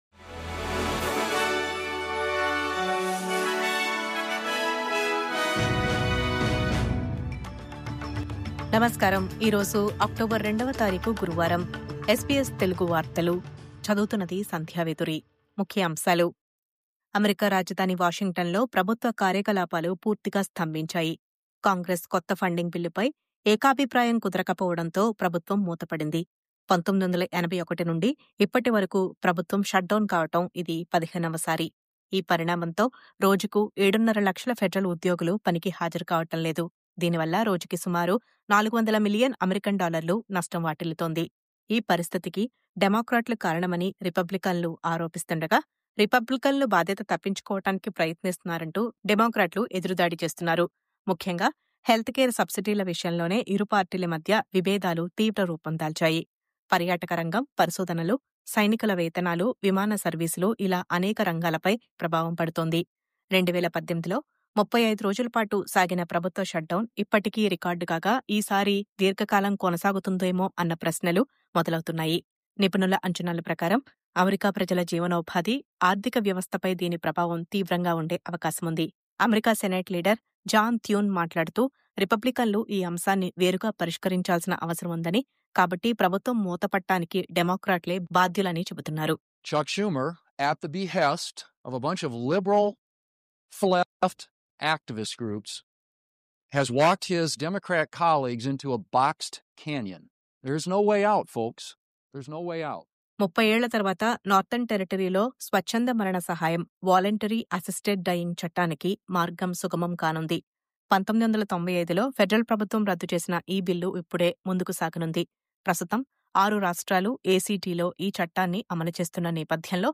News update: US Shutdown...స్తంభించిన అమెరికా ప్రభుత్వ కార్యకలాపాలు..